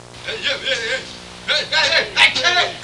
Insane Babbling Sound Effect
Download a high-quality insane babbling sound effect.
insane-babbling.mp3